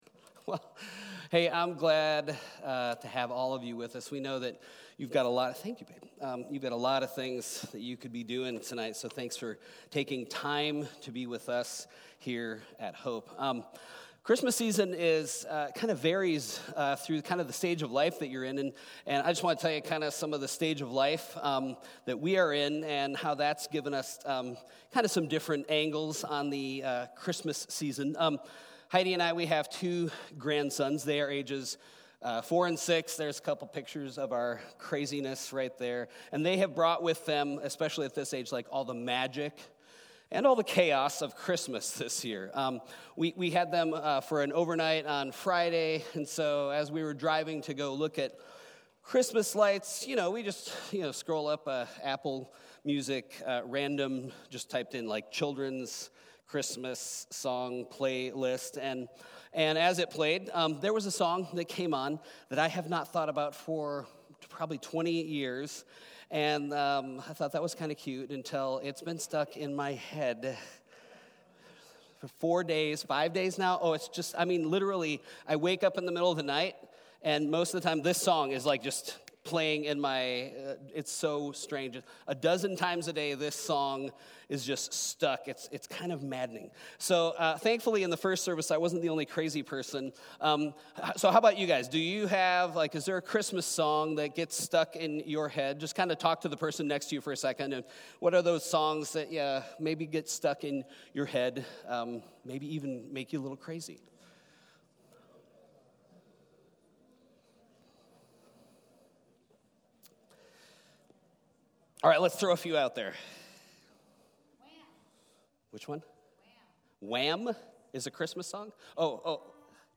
christmas-eve-2024-peace-and-favor-sermon.mp3